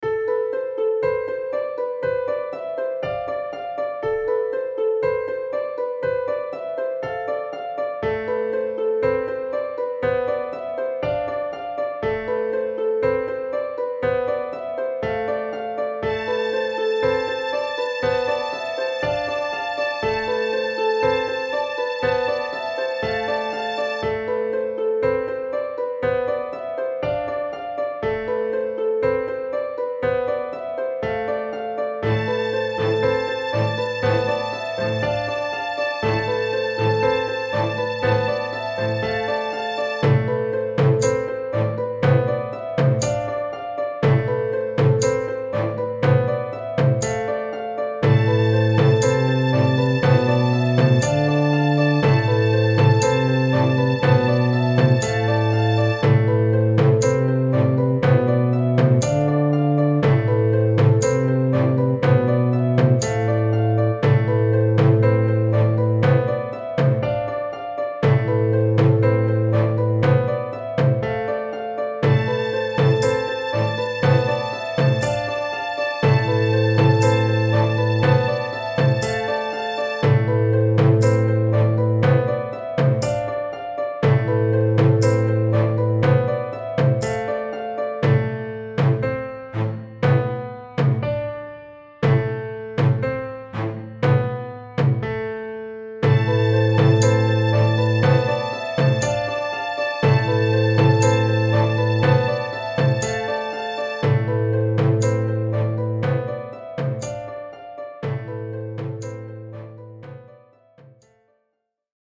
I found the instrument quality somewhat lack lustre though. I would say almost General MIDI or Soundfont like which is a real shame given how easy they are to listen to and engaging.
These are Logic Pro's default samples, with the guitar being slightly tweaked.